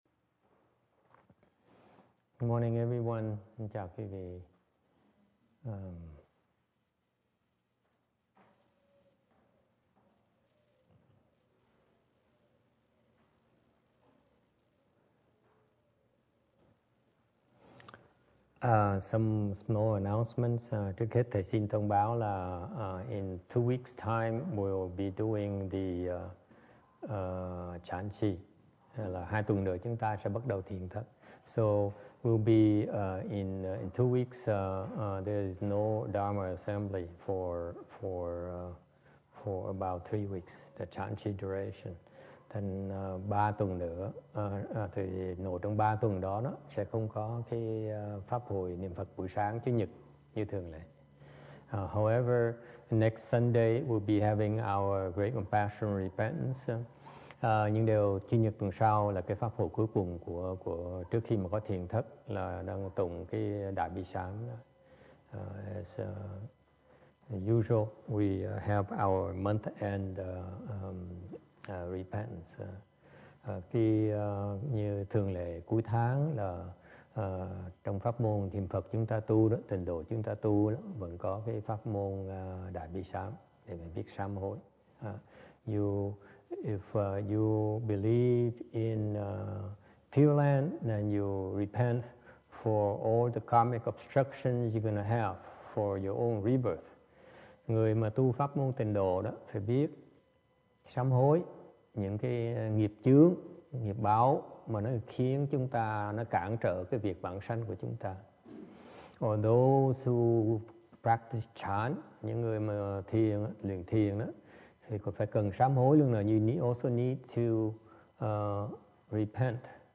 Dharma_Talk_150419_Rebirth_in_Pureland 정토왕생.mp3
영어법문을 MP3로 다운받아 들으시면 음질이 좋습니다.